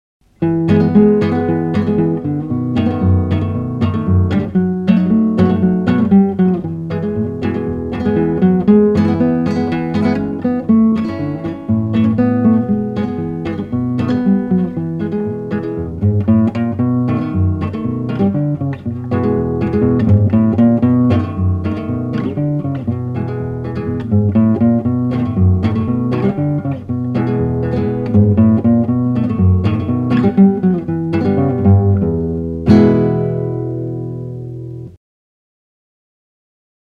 Example from my early guitar-books